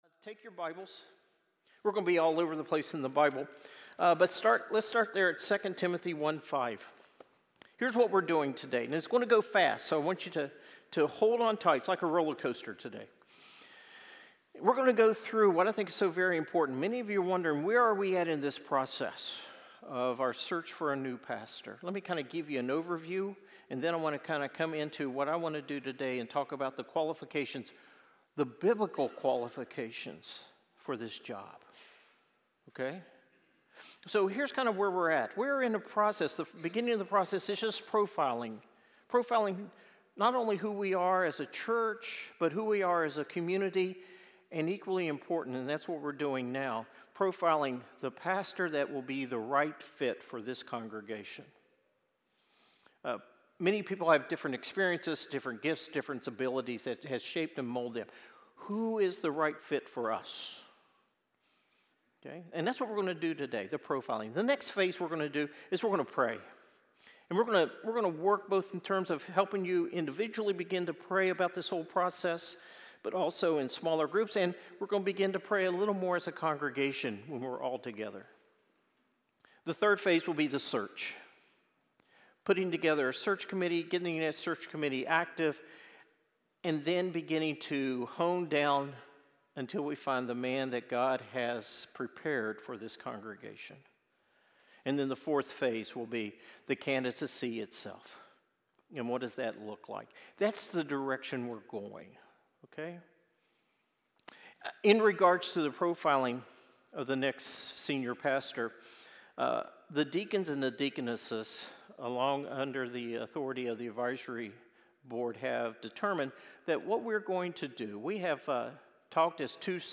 gave a dynamic and fast-paced sermon about the process of finding a new senior pastor for our church. He highlighted the steps our church is taking, from profiling the right candidate and evaluating their conversion, calling, and convictions, to recognizing their character, competence, and compassion.